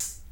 • Soft Sounding Pop Foot Hi Hat Sound D Key 08.wav
Royality free hi-hat sample tuned to the D note. Loudest frequency: 6202Hz
soft-sounding-pop-foot-hi-hat-sound-d-key-08-M5a.wav